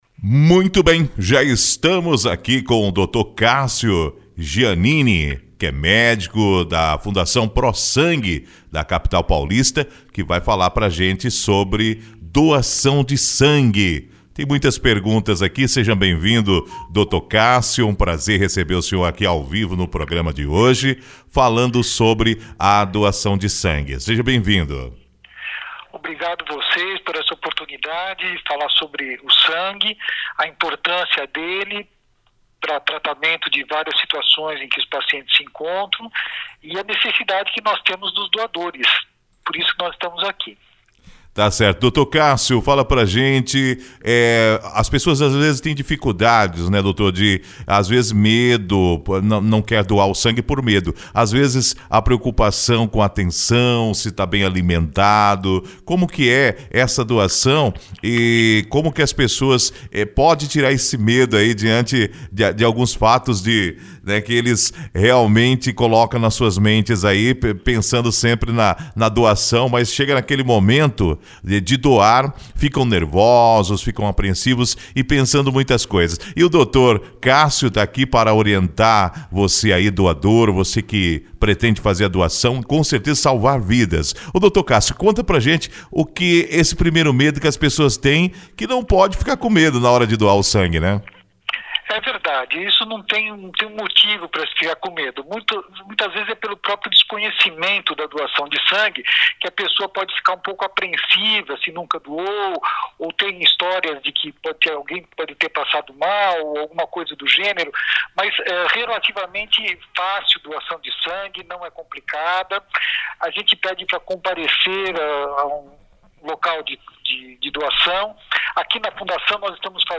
Com estoque em situação bem crítica, Pró-Sangue atende no feriado do Dia do Trabalho Entrevista por telefone com o dr.